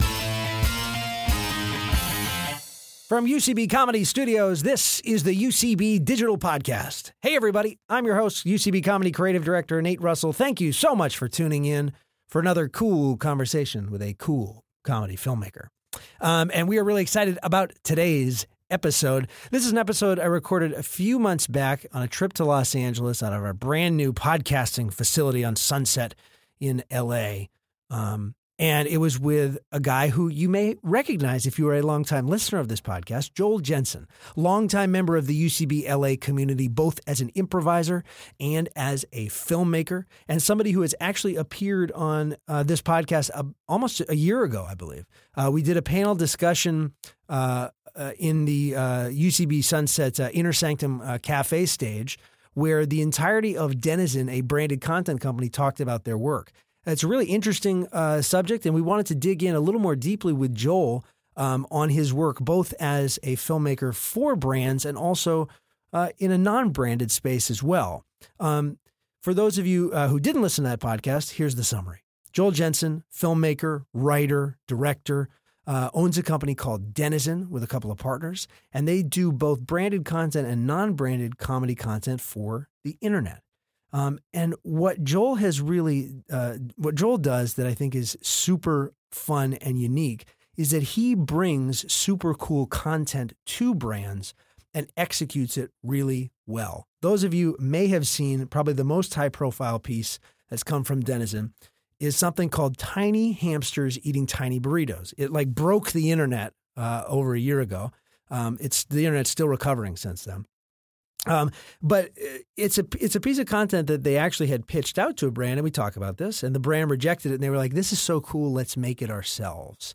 They discus what it means to be a creator, how the lines are blurring between content and advertisement, and how improv and comedy can impact your professional life. Recorded at UCB Comedy Studios West in Los Angeles.